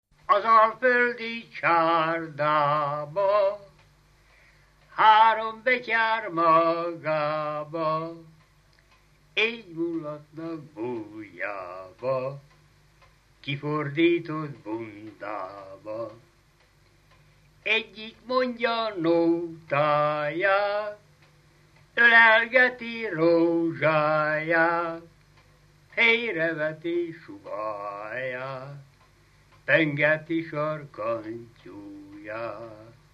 Dunántúl - Baranya vm. - Szilvás
ének
Stílus: 1.1. Ereszkedő kvintváltó pentaton dallamok
Szótagszám: 7.7.7.7
Kadencia: 7 (5) b3 1